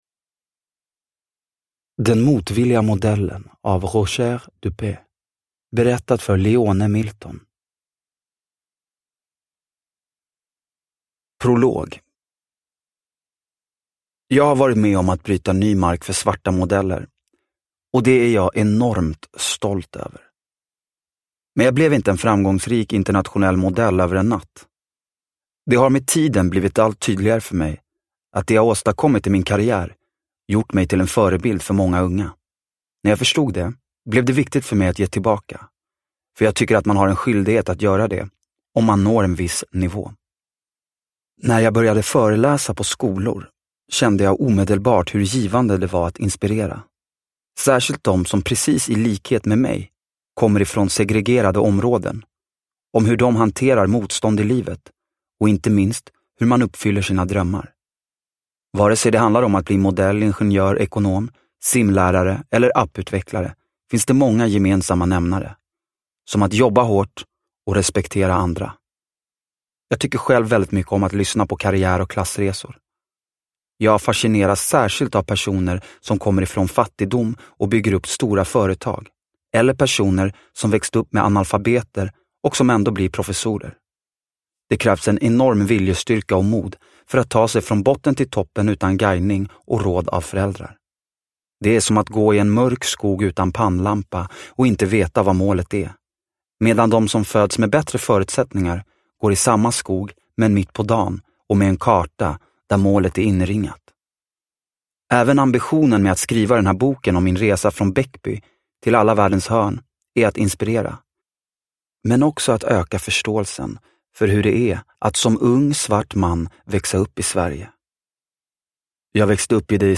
Den motvilliga modellen – Ljudbok – Laddas ner